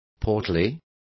Complete with pronunciation of the translation of portly.